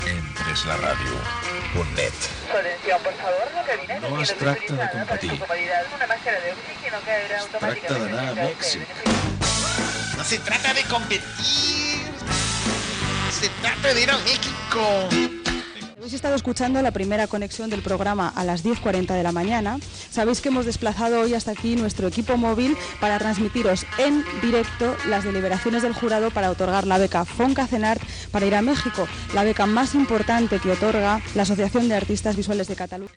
Indicatius del projecte "Hem pres la ràdio"